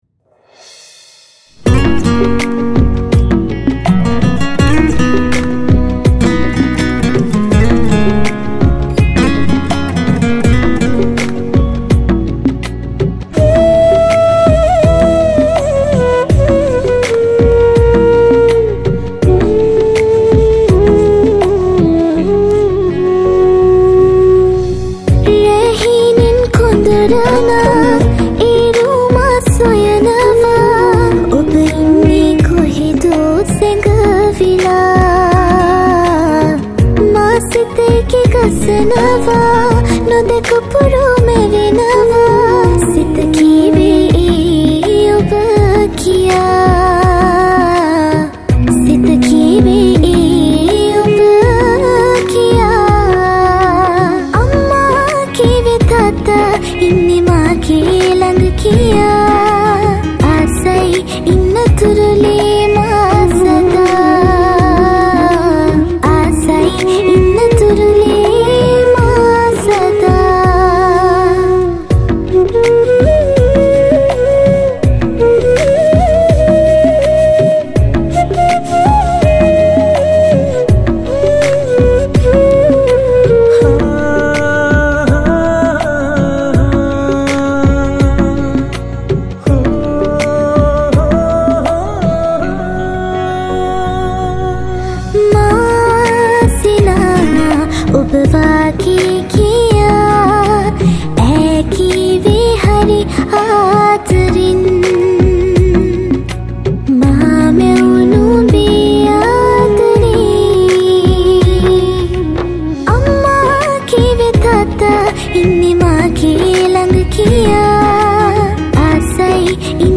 Flute
Guitar